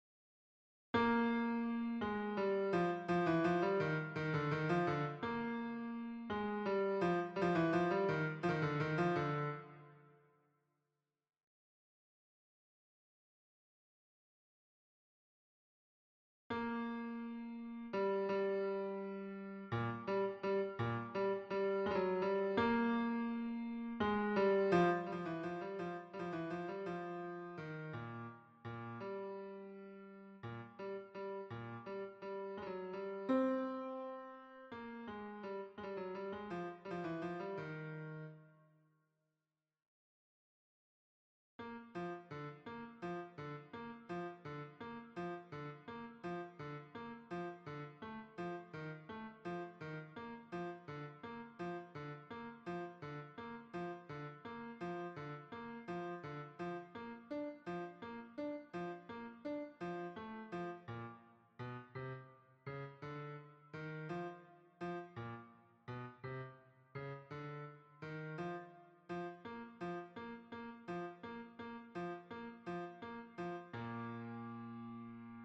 Basses
La_Traviata_BASSES_Libiamo_ne_lieti_calici.mp3